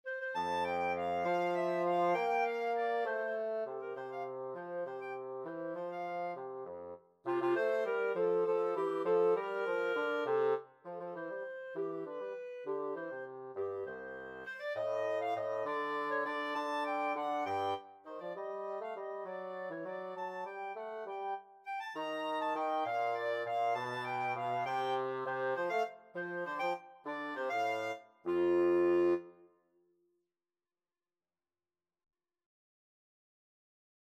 3/8 (View more 3/8 Music)
Classical (View more Classical Clarinet-Bassoon Duet Music)